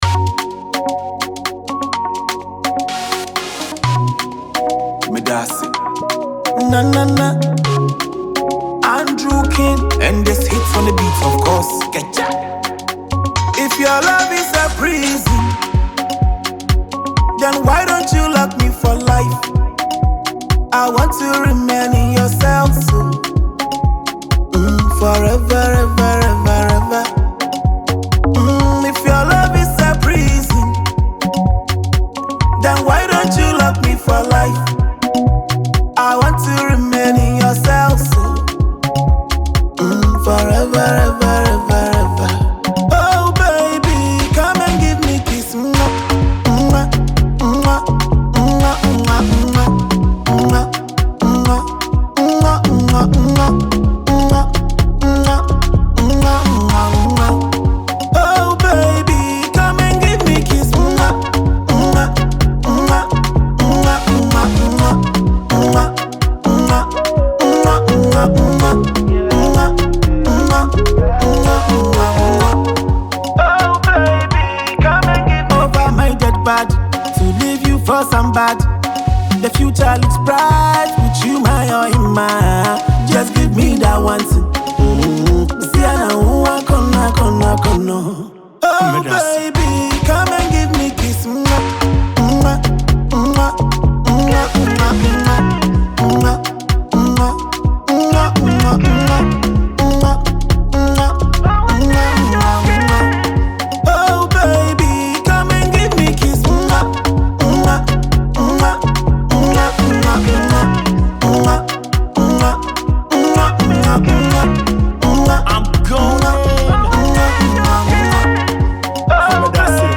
Afrobeat
With its high energy tempo and catchy sounds